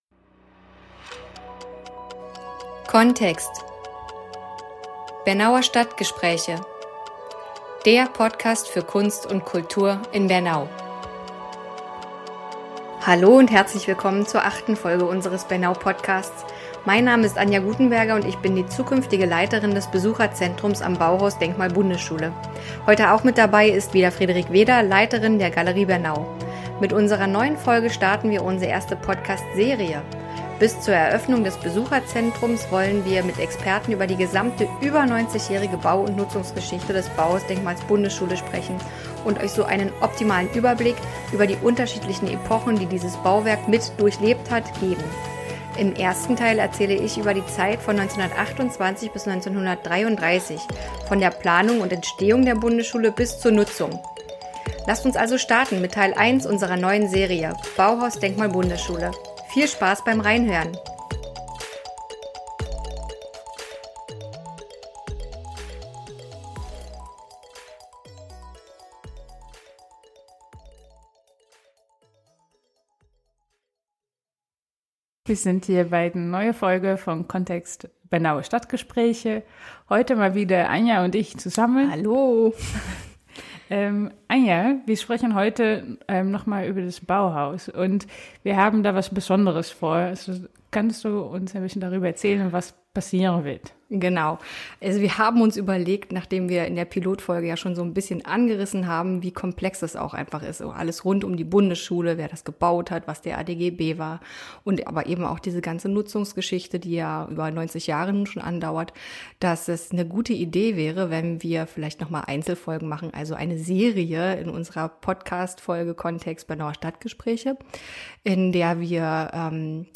Bis zur Eröffnung des Bauhaus-Besucherzentrums im Herbst 2021 führen wir Gespräche mit Experten zur über 90-jährigen Bau- und Nutzungsgeschichte des Bauhausensembles, um so abschließend...